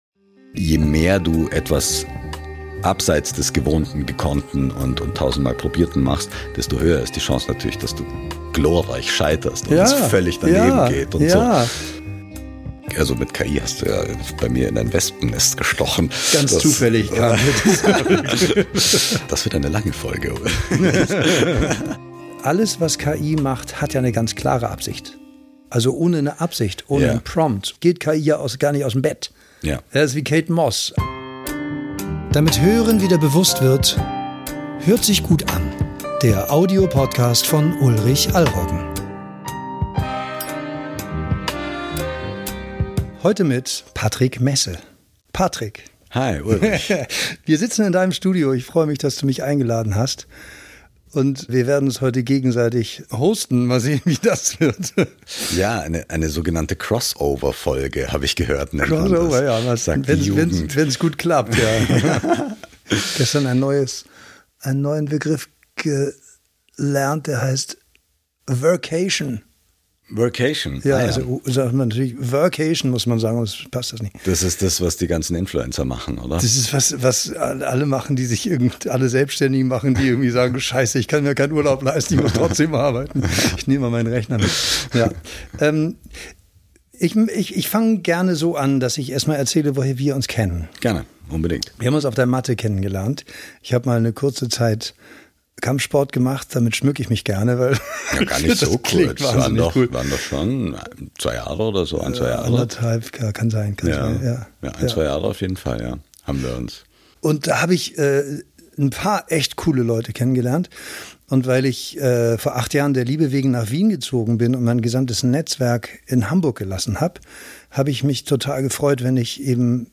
Und dann ist da noch diese Stimme!
Das Gespräch hat uns beiden einen Mordsspaß gemacht und wir freuen uns, wenn ihr beim Hören ebenso viel Freude habt.